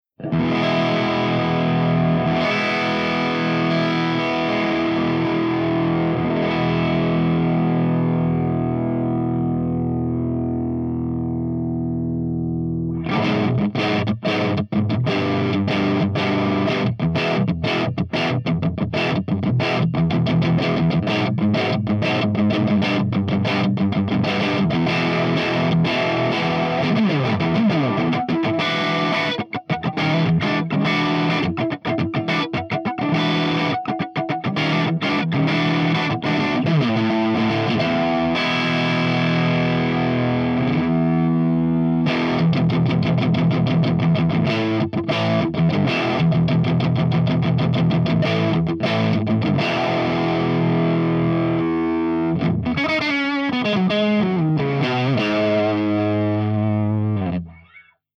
207_MESASTUDIOCRUNCH_V30_SC.mp3